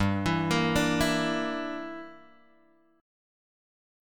G Minor 6th